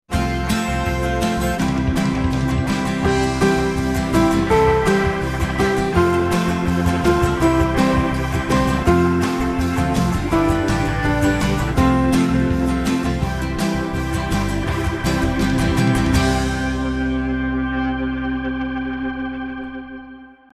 psalm refrains